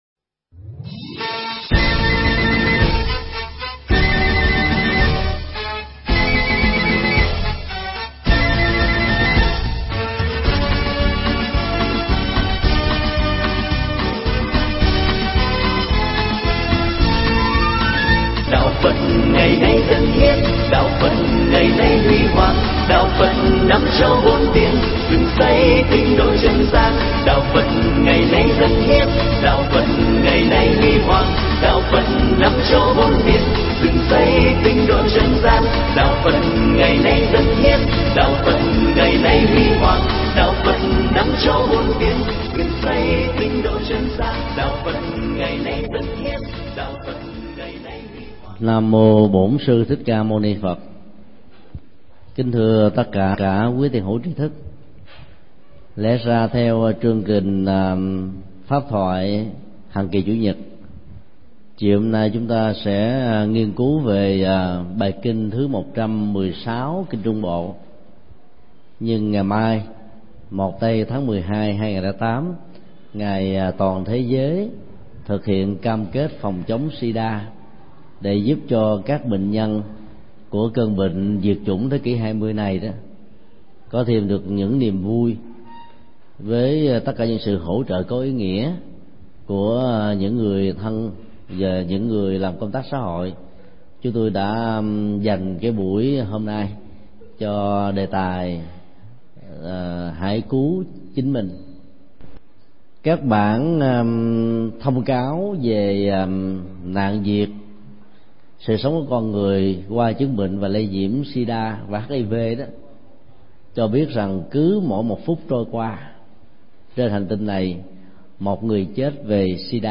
Mp3 Pháp Thoại Hãy cứu lấy mình – Thượng Tọa Thích Nhật Từ giảng tại Chùa Xá Lợi, nhân ngày Quốc tế phòng chống HIV/AIDS, ngày 30 tháng 11 năm 2008